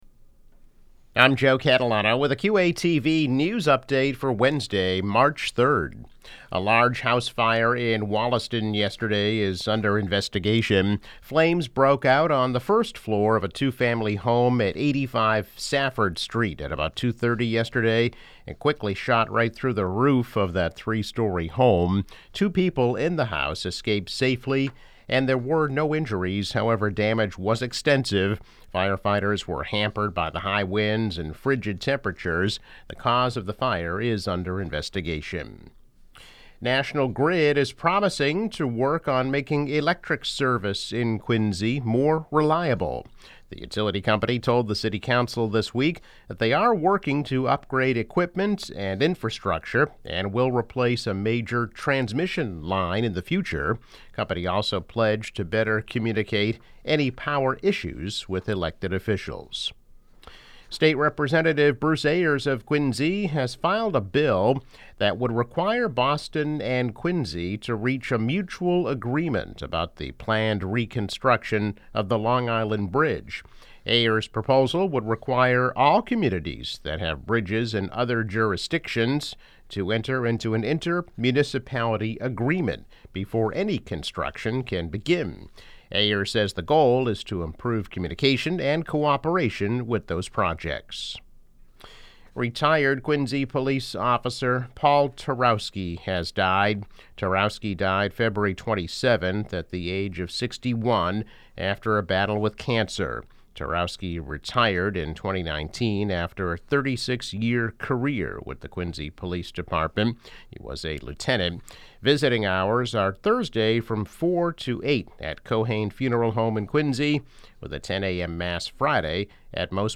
News Update - March 3, 2021